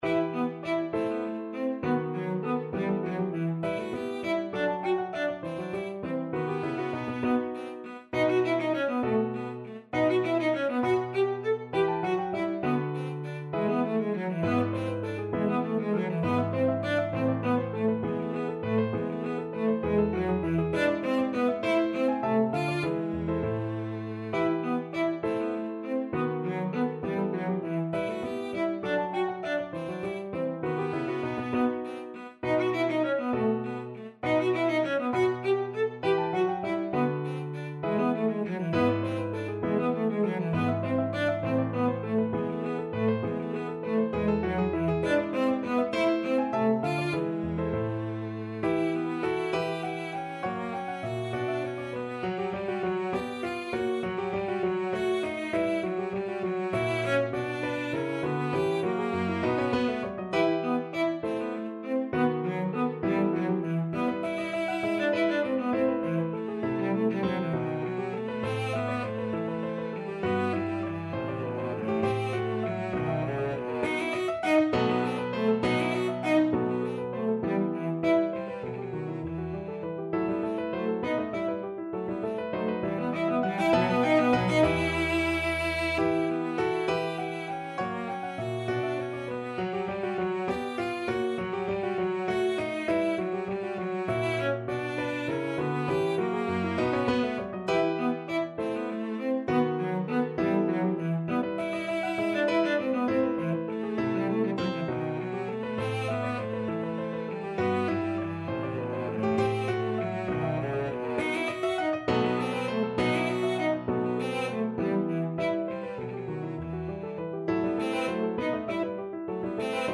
Classical Vivaldi, Antonio Sonata No. 5 in E Minor, Op. 14, Fourth Movement Cello version
Cello
~ = 100 Allegro (View more music marked Allegro)
3/8 (View more 3/8 Music)
A3-A5
Classical (View more Classical Cello Music)
vivaldi_sonata_em_op14_5_4_VLC.mp3